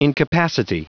Prononciation du mot incapacity en anglais (fichier audio)
Prononciation du mot : incapacity